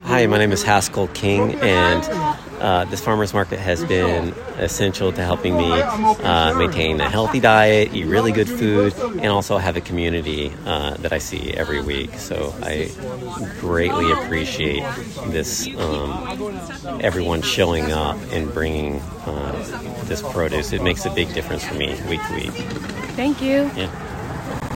Listen to testimonials from our community members.